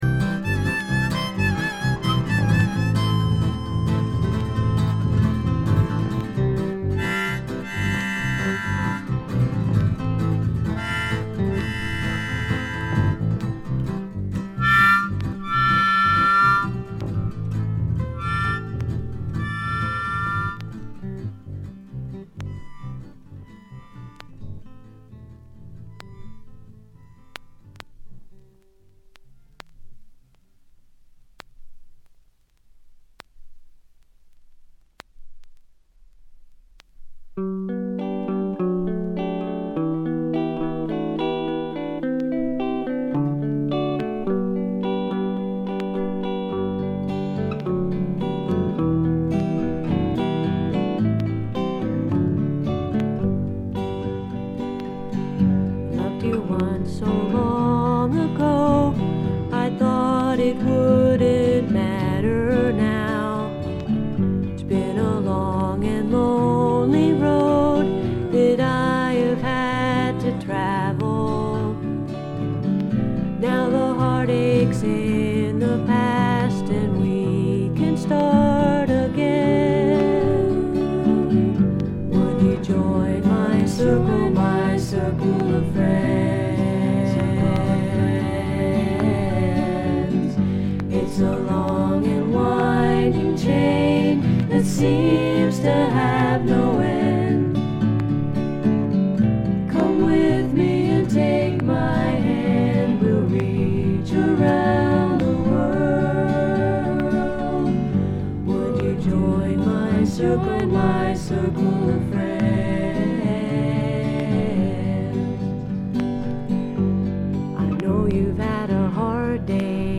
B4終盤からB5前半にかけて間欠的に周回気味のノイズがでます。
試聴曲は現品からの取り込み音源です。
※B4終盤〜B5。ノイズの状況をご確認ください。